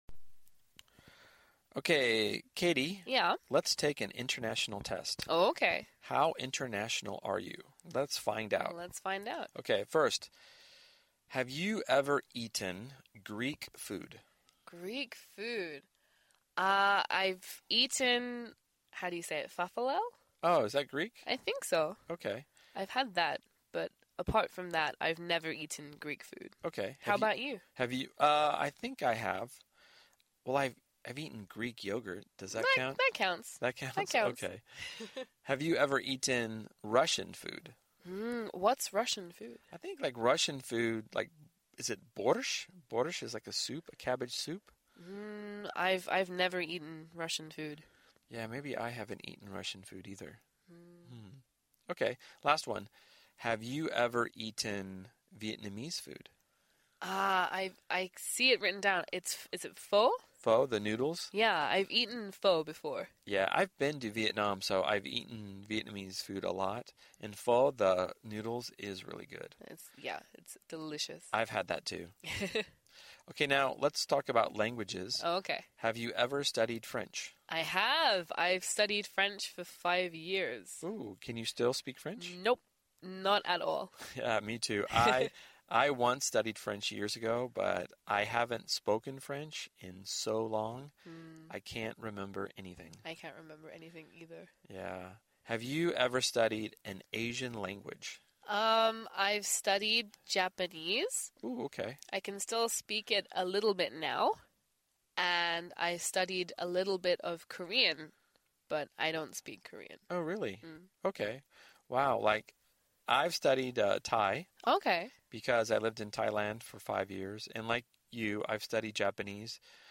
实战口语情景对话 第1180期:How International are you? 你有多国际化?